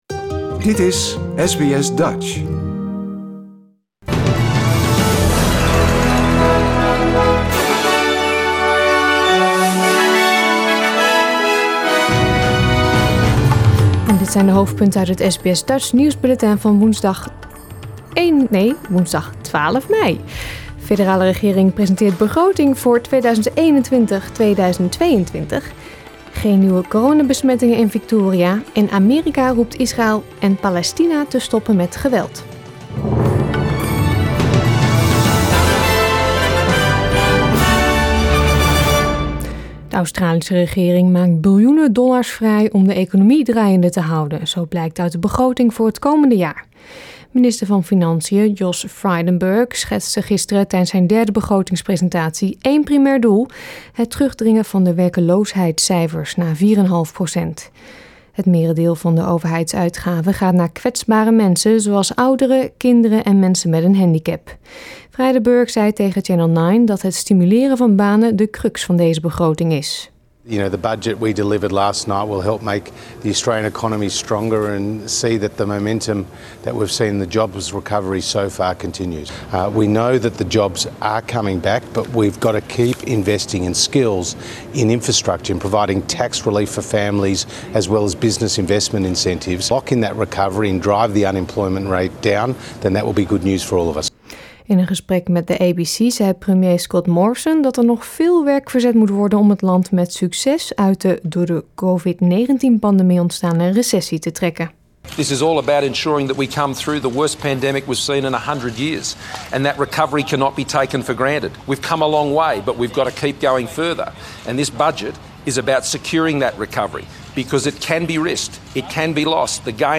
Nederlands/Australisch SBS Dutch nieuwsbulletin van woensdag 12 mei 2021